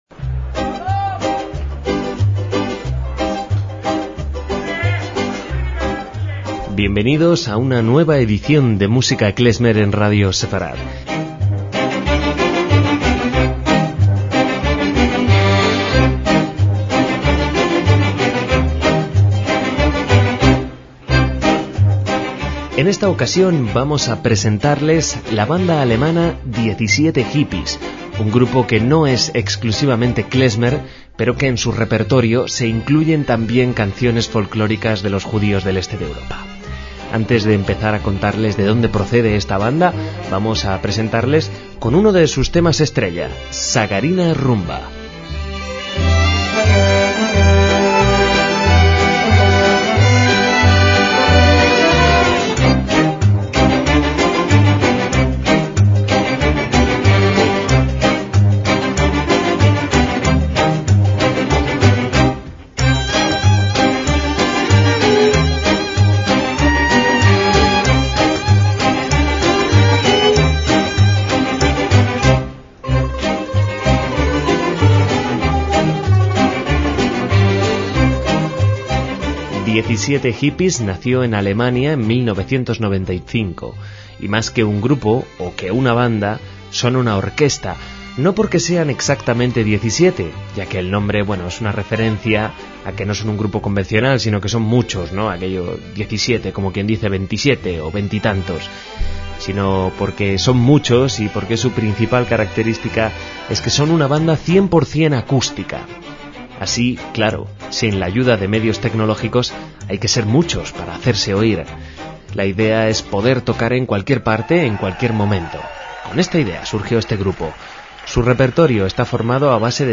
MÚSICA KLEZMER - 17 Hippies es una banda instrumental de Berlín que no sólo toca música klezmer sino folklórica (¡y energética!) de países de Europa Central y del Este.